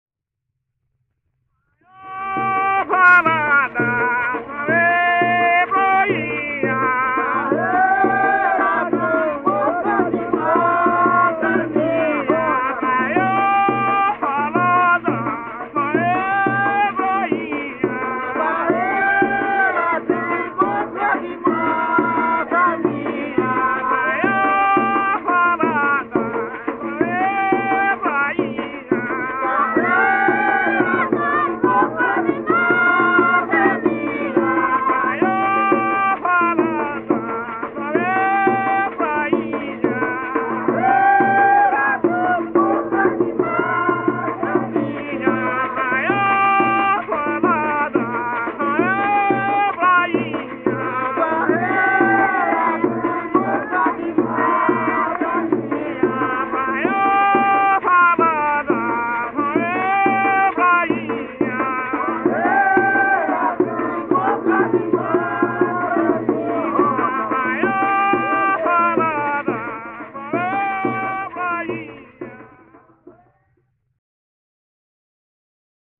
Coco solto